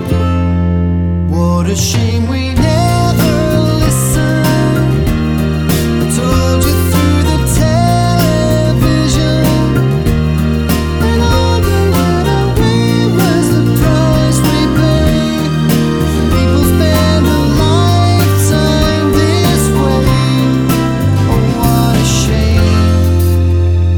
For Solo Singer Duets 3:48 Buy £1.50